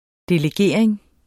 Udtale [ deleˈgeˀɐ̯eŋ ]